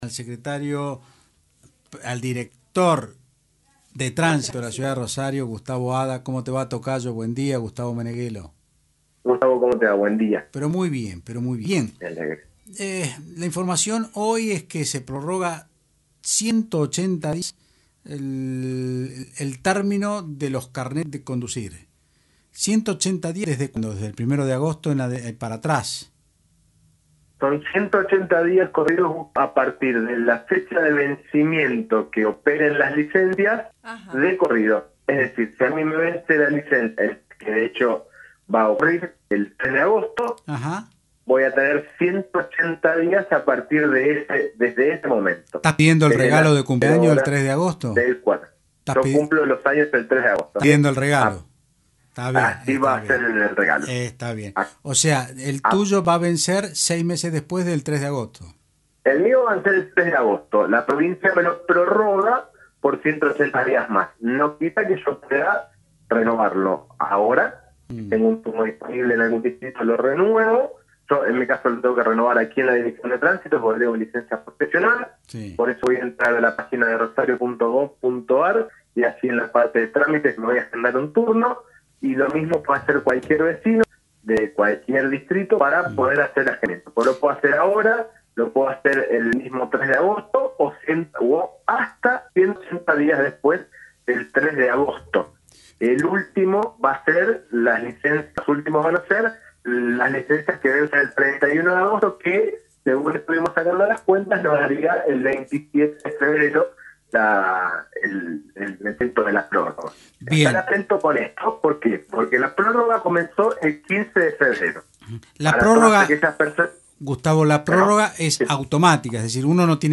El director de Tránsito Gustavo Adda, aclaró en Otros Ámbitos (Del Plata Rosario 93.5  que la extensión es por 180 días corridos y que los centros de distrito y otras oficinas del municipio ya toman turnos para la actualización.